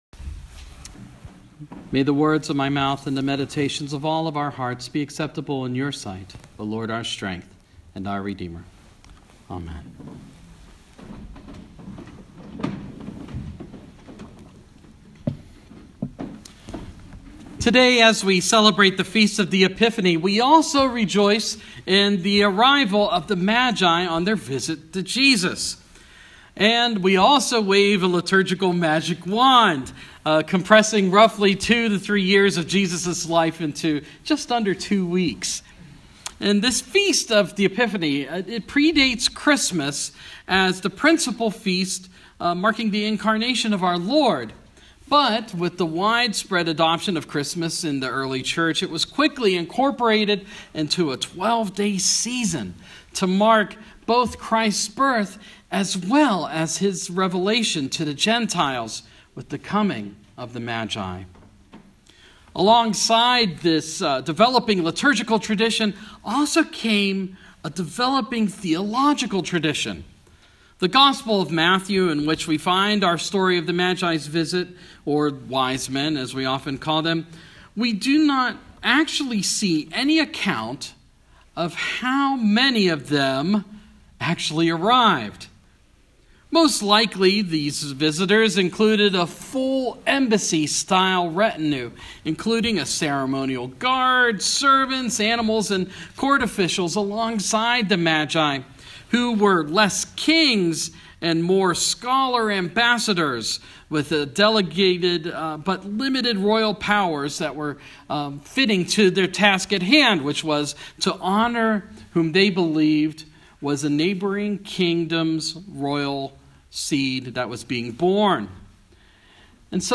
Another feast day, another sermon!
epiphany-homily-2016.m4a